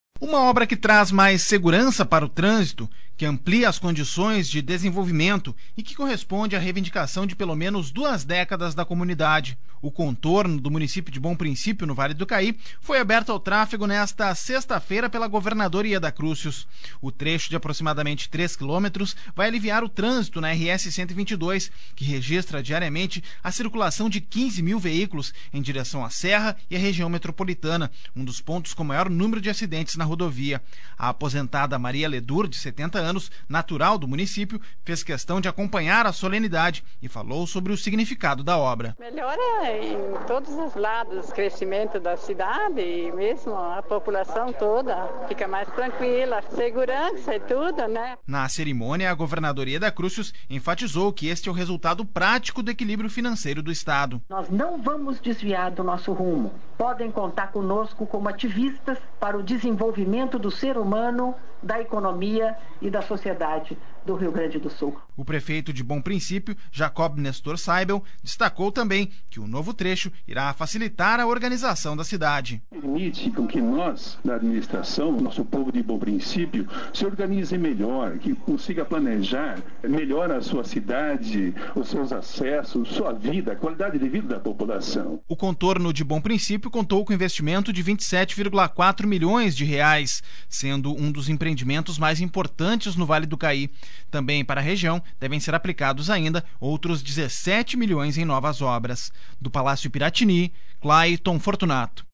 Fogos de artifício e aplausos da população com a passagem de veículos marcaram nesta sexta-feira (15) a entrega pela governadora Yeda Crusius de um contorno rodoviário de 2,8 quilômetros, em Bom Princípio.